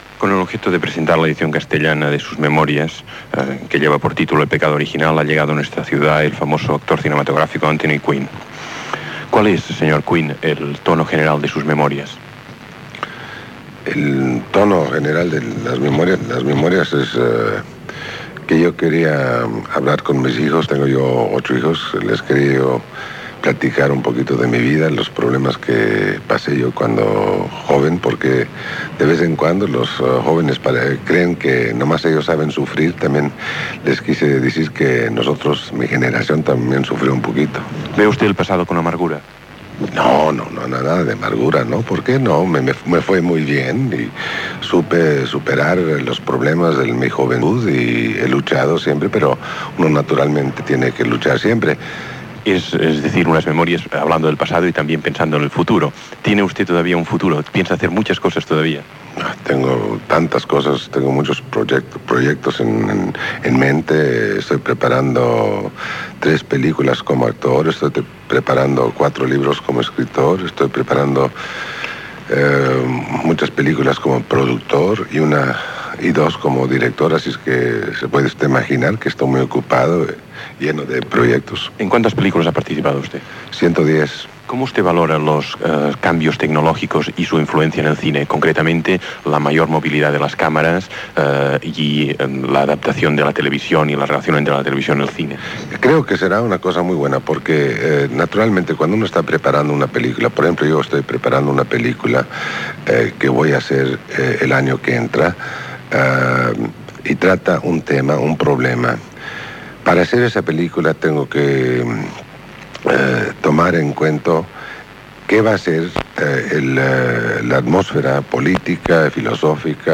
Entrevista a l'actor Anthoy Quinn, autor del llibre "El pecado original" on recull les seves memòries.
Informatiu